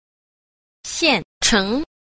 6. 現成 – xiànchéng – hiện thành (hoàn thành)